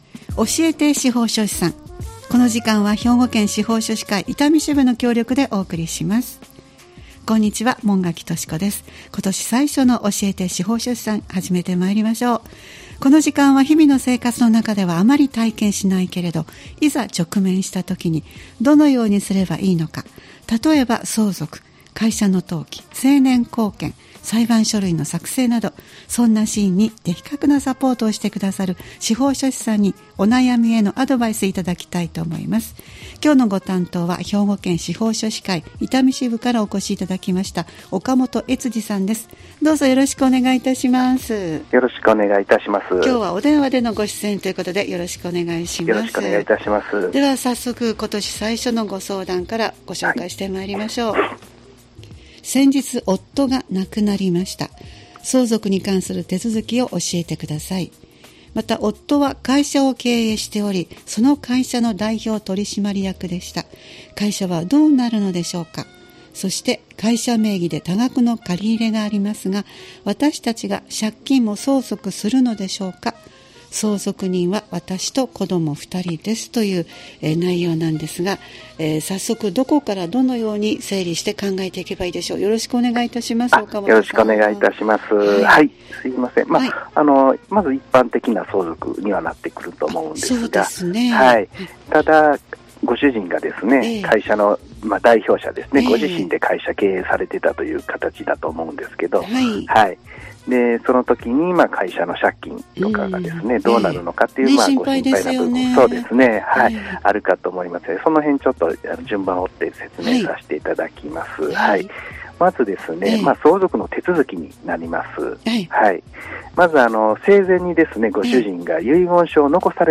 毎回スタジオに司法書士の方をお迎えして、相続・登記・成年後見・裁判書類の作成などのアドバイスをいただいています。